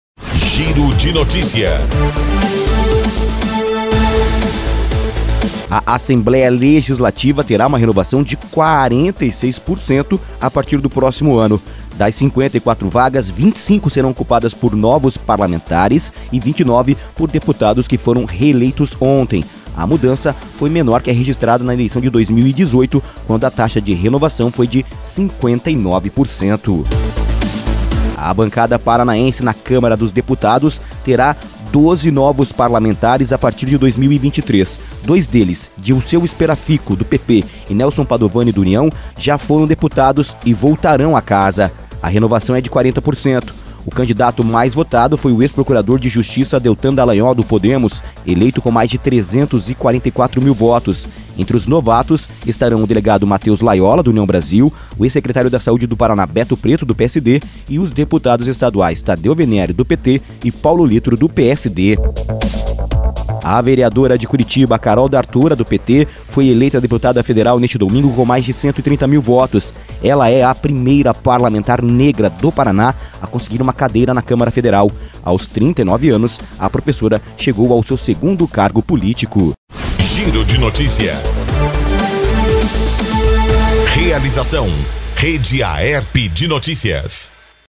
Giro de Notícias – Edição da Manhã